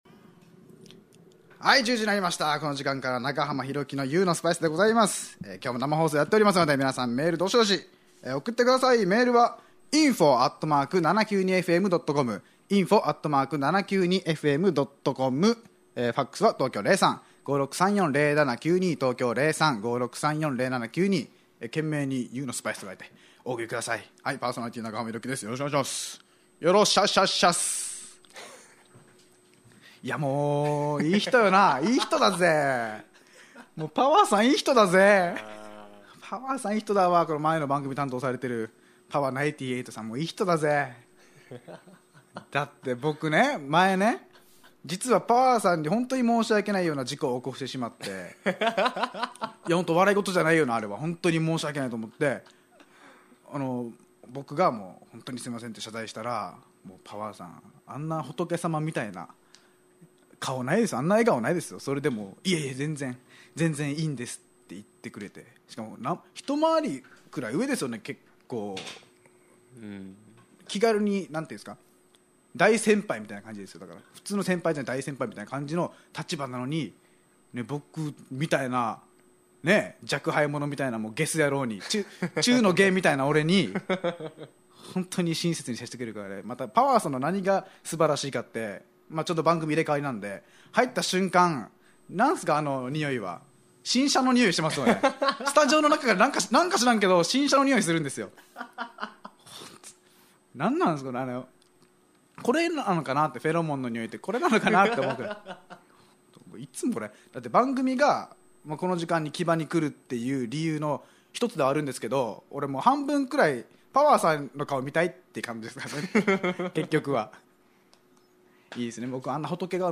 レインボータウンFMからお届けしている番組
脳みそ震撼、不敵なトークバラエティ！